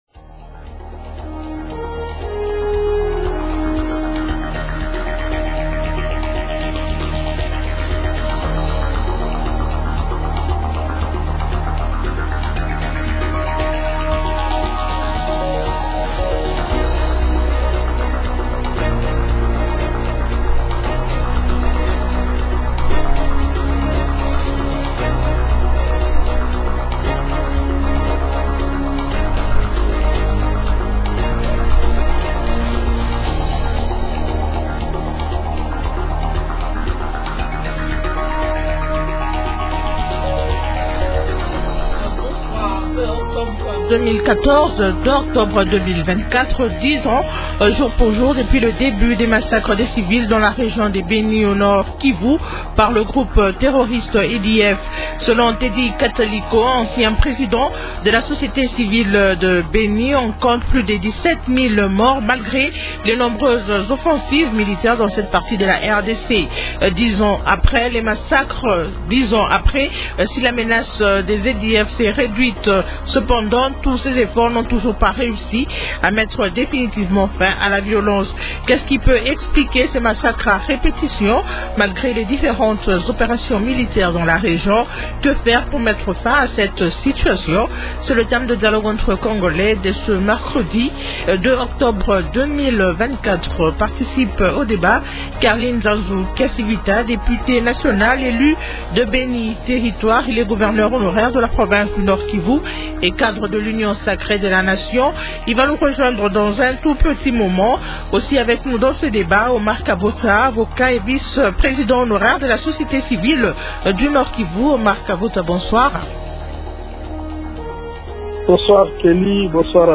Invités : -Carly Nzanzu Kasivita, député national élu de Beni territoire.